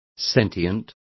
Complete with pronunciation of the translation of sentient.